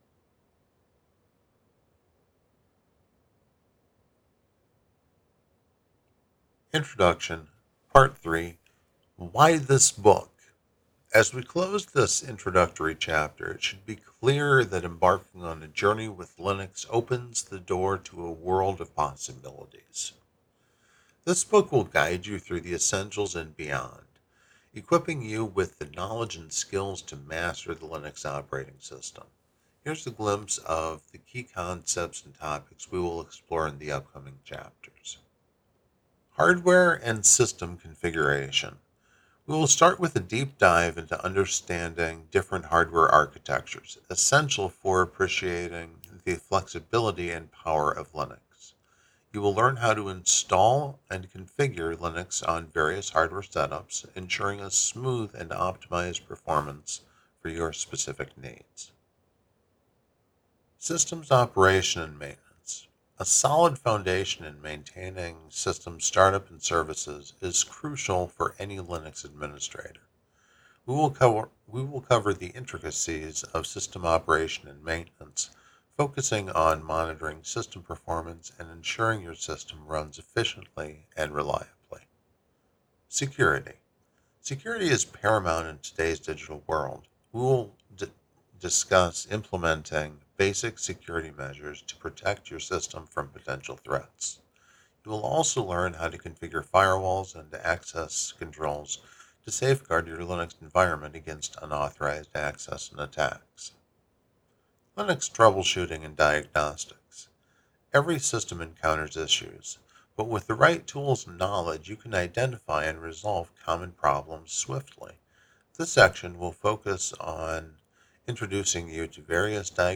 'Audiobook